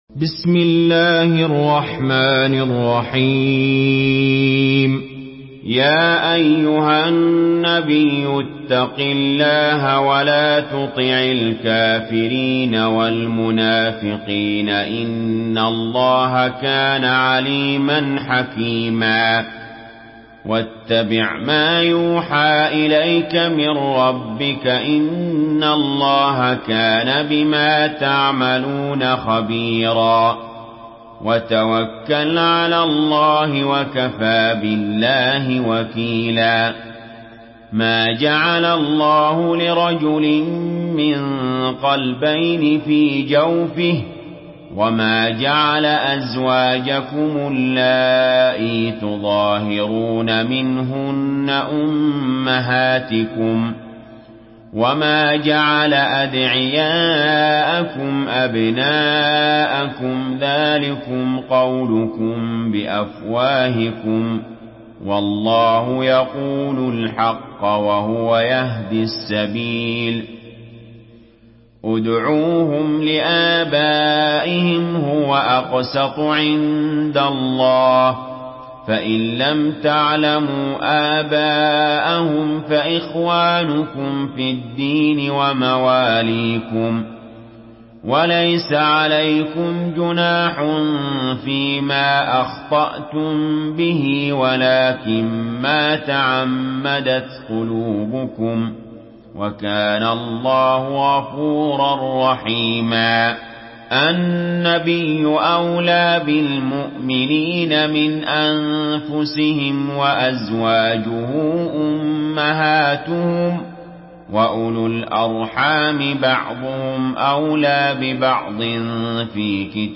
Surah Ahzab MP3 by Ali Jaber in Hafs An Asim narration.
Murattal Hafs An Asim